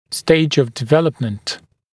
[steɪʤ əv dɪ’veləpmənt][стэйдж ов ди’вэлэпмэнт]стадия развития, стадия формирования